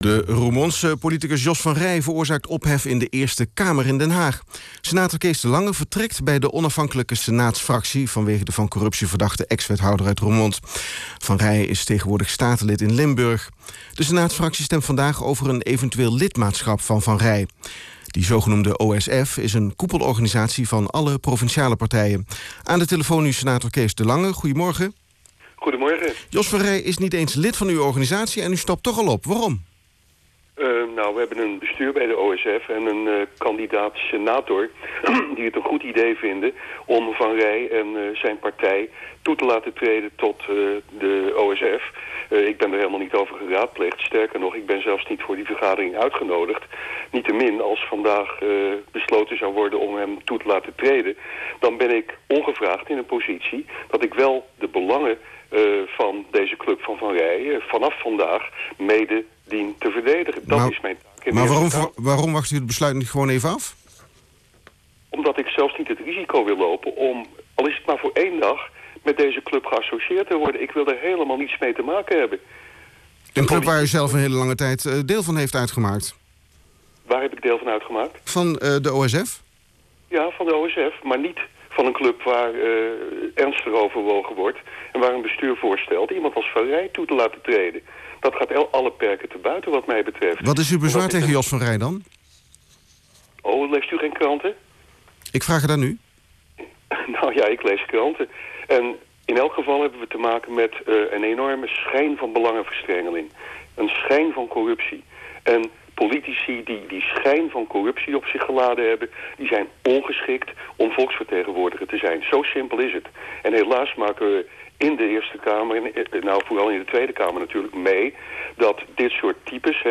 Later op diezelfde dag werd mijn vertrek bij de OSF gepubliceerd op de website van de NOS. Op zaterdagmorgen werd ik geïnterviewd door L1 Radio in Limburg.
kees-de-lange-op-l1-radio.wav